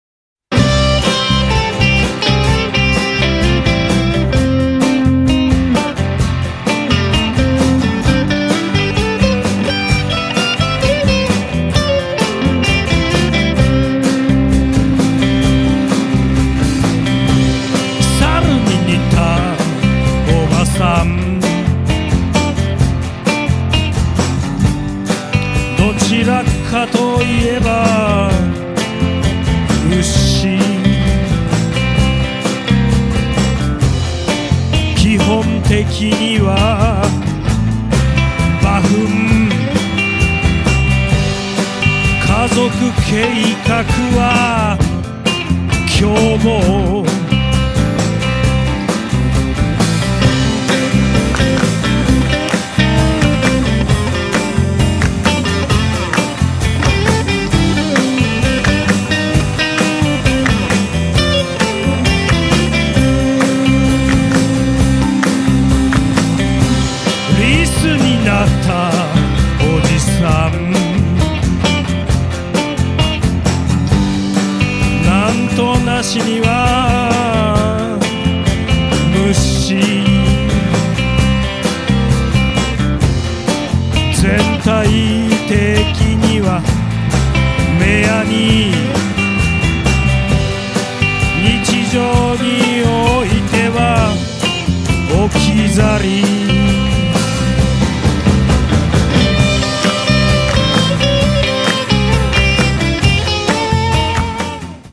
ギター
ベース
ドラム